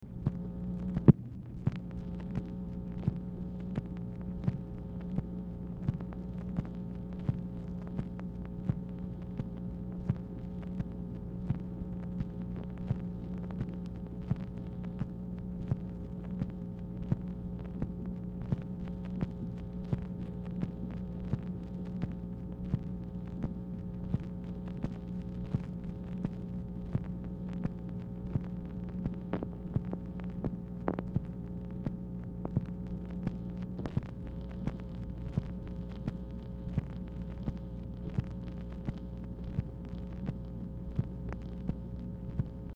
Telephone conversation # 11567, sound recording, MACHINE NOISE, 2/27/1967, time unknown | Discover LBJ
Format Dictation belt
Specific Item Type Telephone conversation